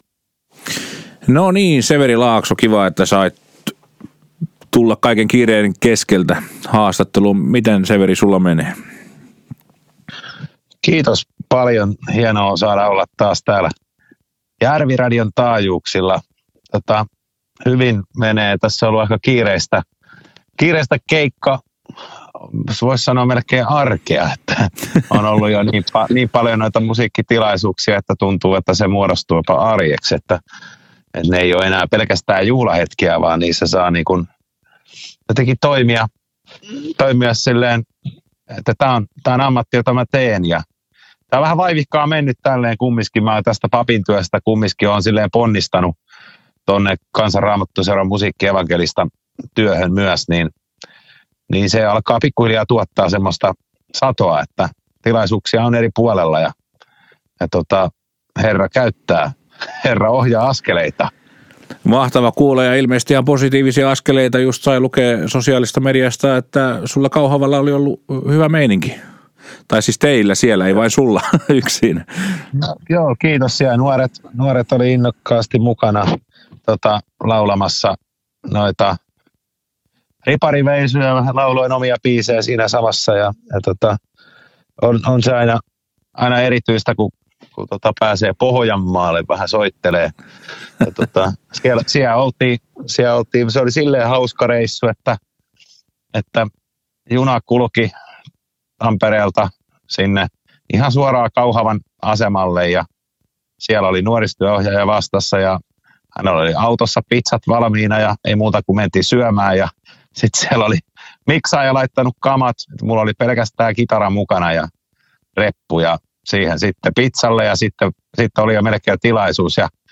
Haastattelijana